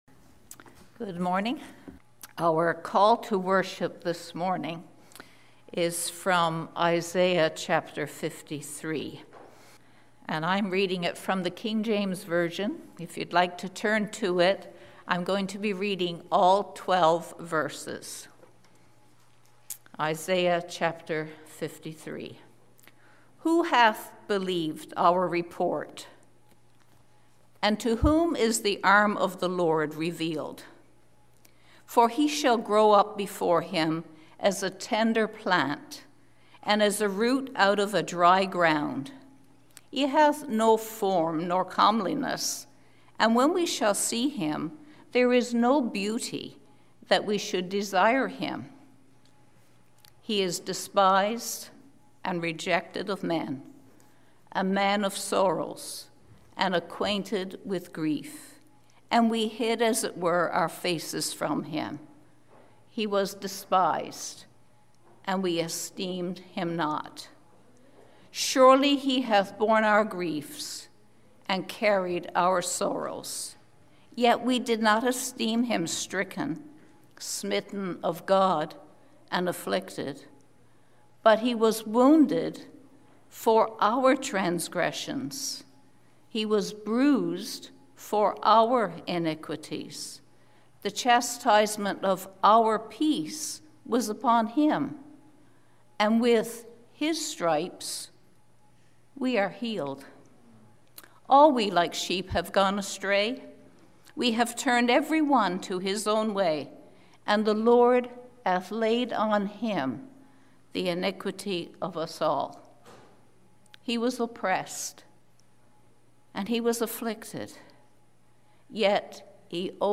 Sabbath service of the St. John's Seventh-day Adventist Church